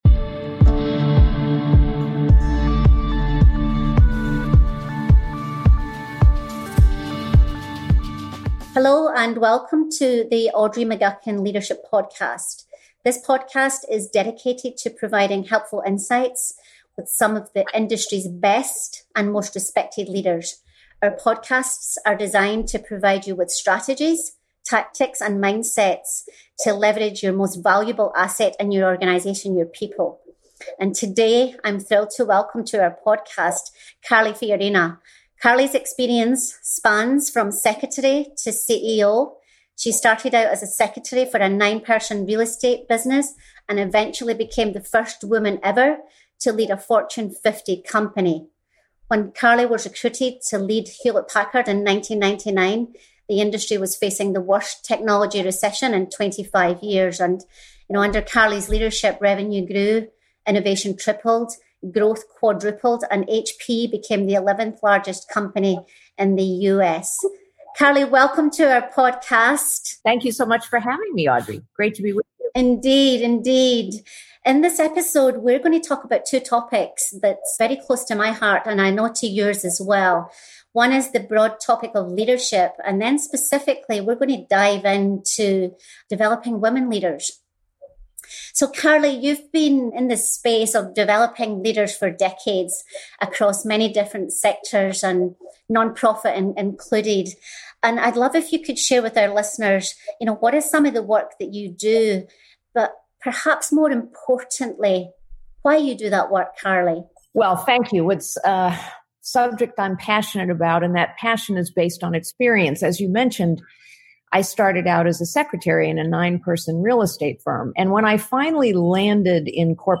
Listen to C-Level Executives discuss the key capabilities of great leaders, specifically women leaders.